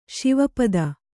♪ Śiva pada